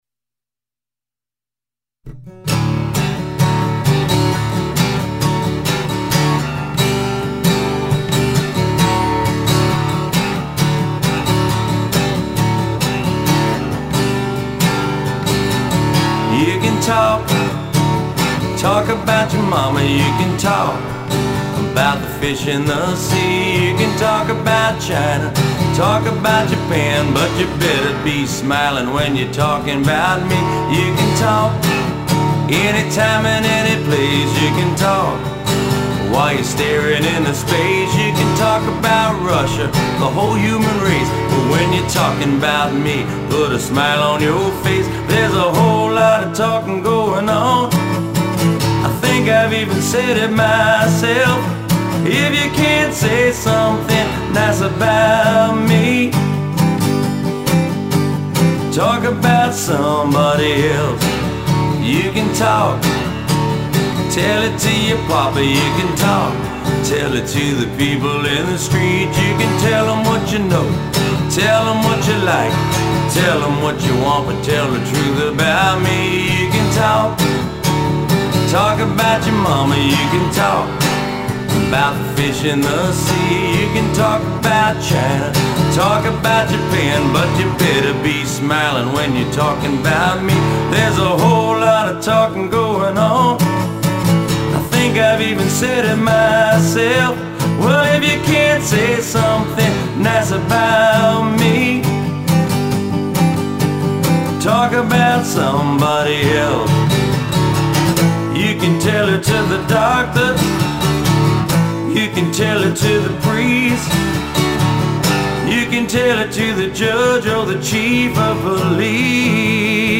All solo performances